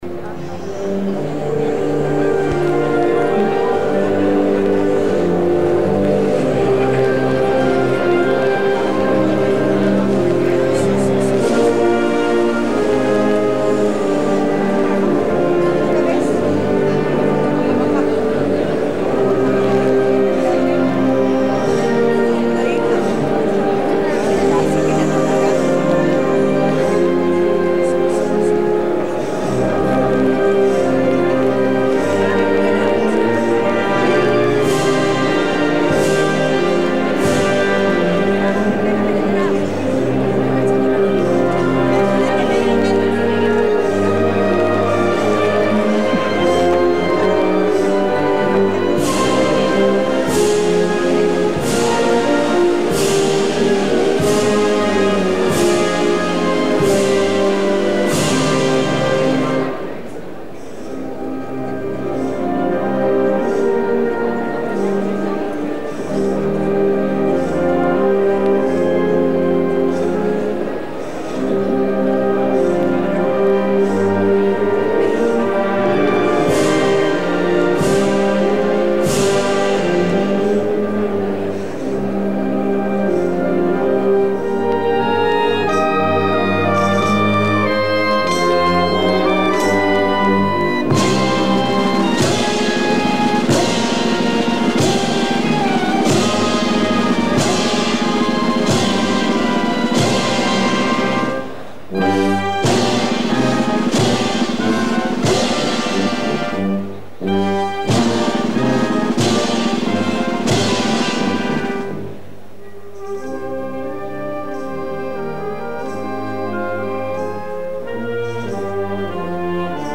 Pregón Semana Santa 2007, Jerez
18 Febrero 2007 Emisión Cadena Ser - Radio Jerez